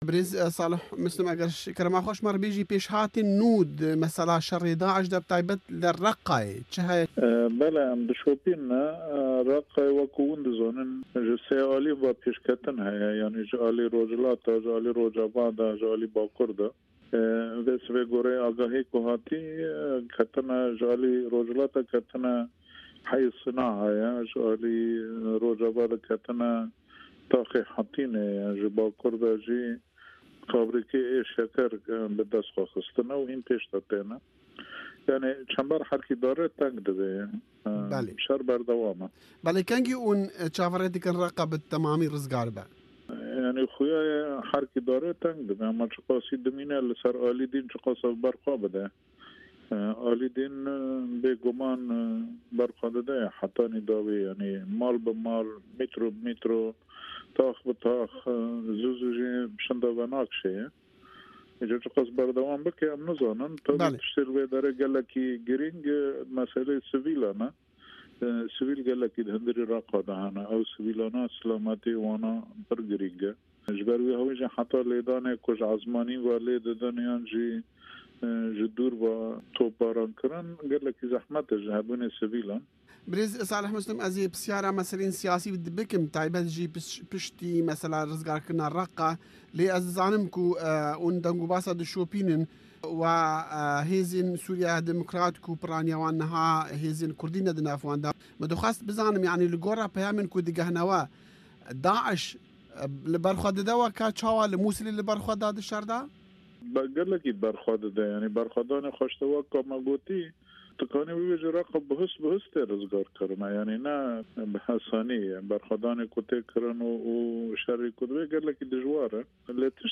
Hevpeyvin - Salih Muslim